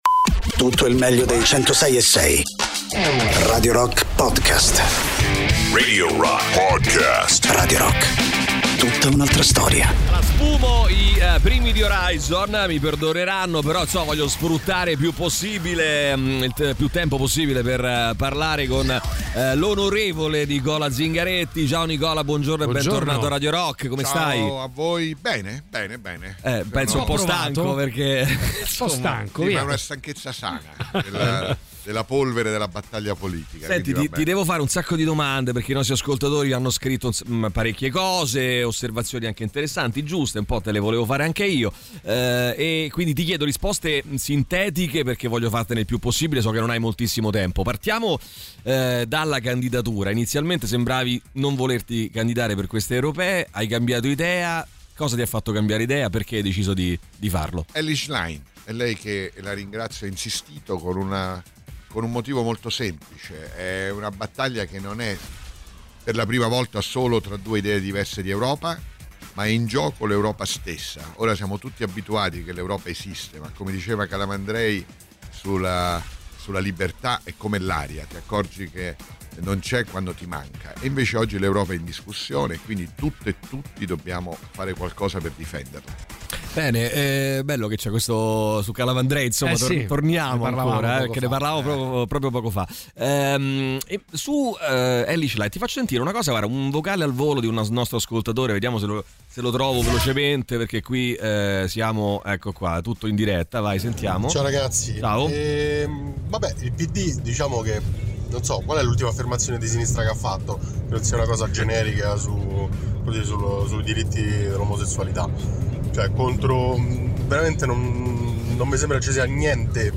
Interviste: Nicola Zingaretti (07-06-24)